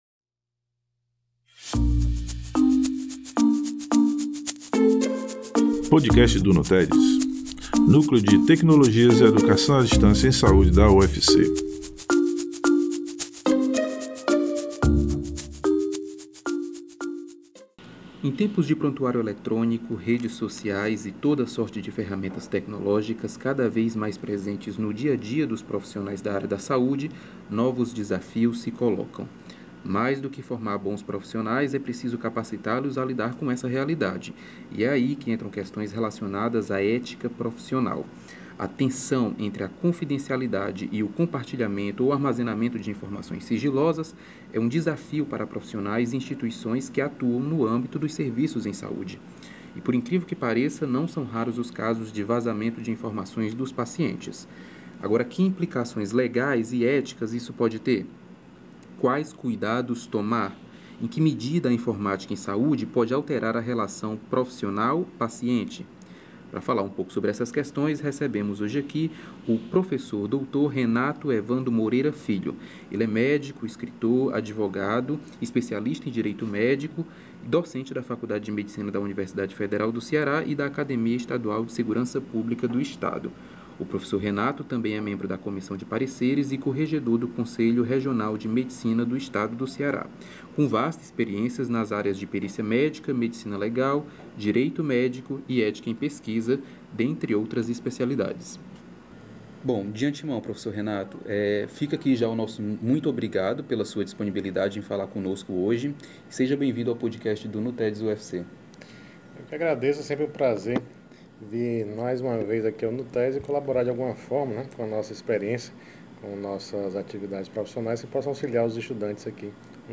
Em tempos de prontuário eletrônico e redes sociais, novos desafios se apresentam para os profissionais da área da saúde. E é nessa perspectiva que esta edição do podcast do NUTEDS/FAMED/UFC apresenta uma entrevista em áudio com o médico